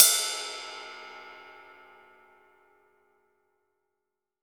M20 RIDE2.wav